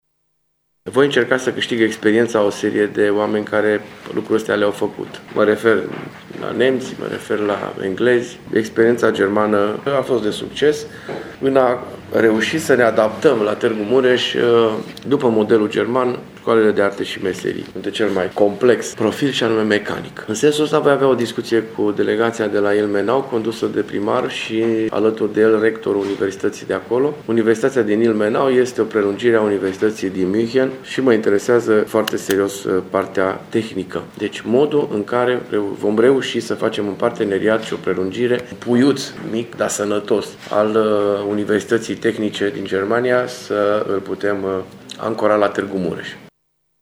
Primarul Dorin Florea a anunțat astăzi, într-o conferință de presă, că va avea discuții pe această temă cu delegațiile străine care sosesc la Tg.Mureș cu prilejul Zilelor orașului.
Dorin Florea: